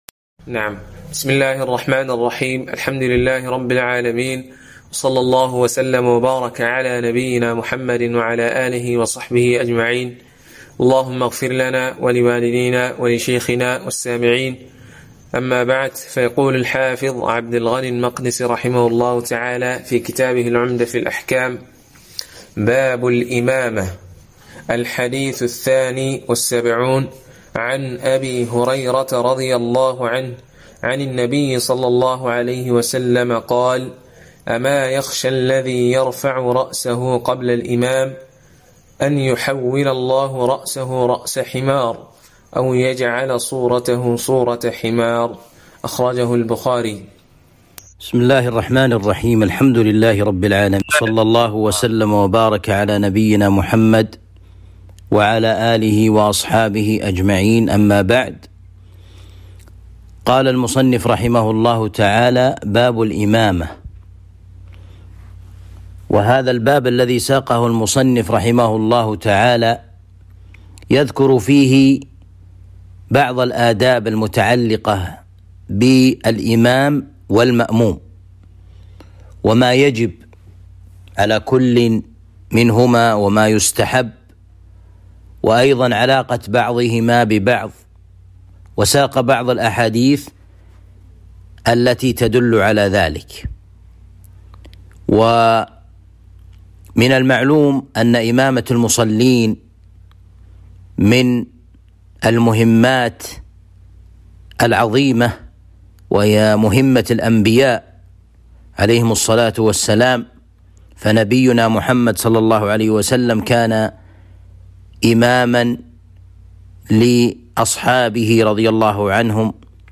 شرح عمدة الأحكام الدرس العشرون {٢٠}